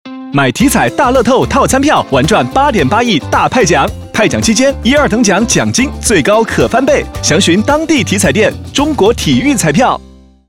2025体彩大乐透8.8亿派奖遇上套餐票10s-男版